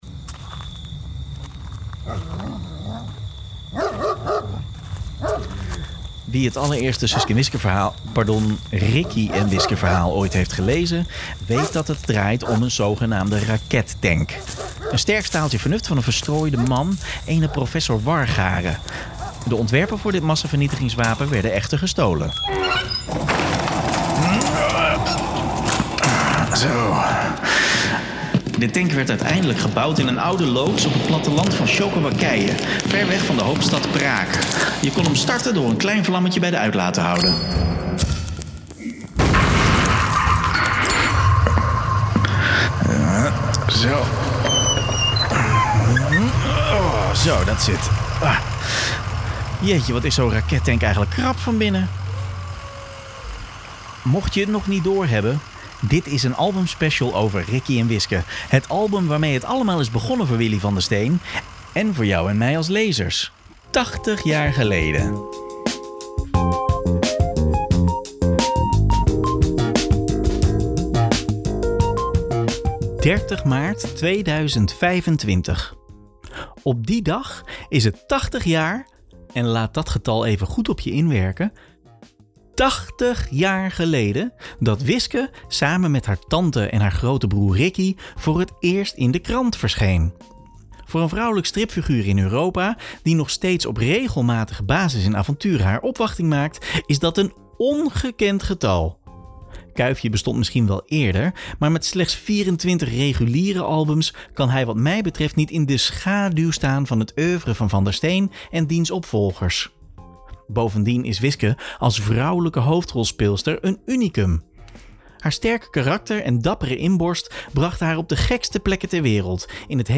In deze reeks gesprekken praat ik met kenners, makers (waaronder een tweeluik met Paul Geerts) en verzamelaars over het verleden, heden en de toekomst van de strips.